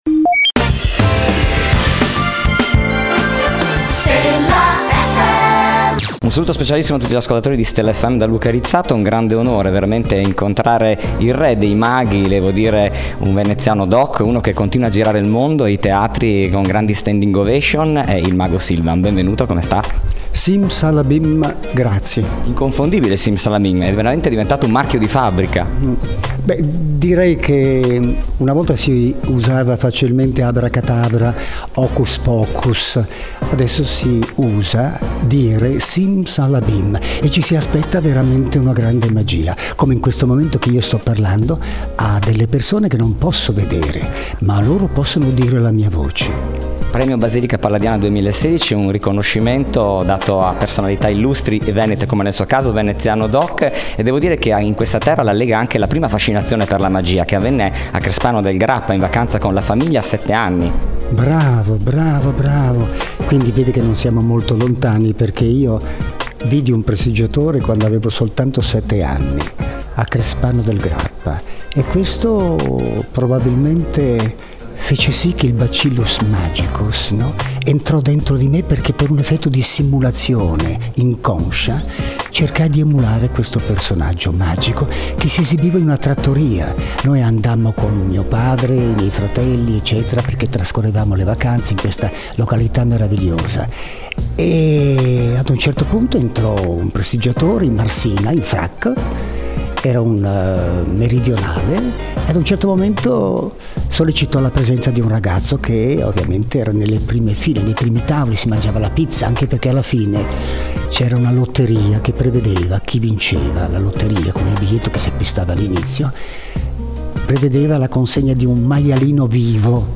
Intervista Mago Silvan | Stella FM
INTERVISTA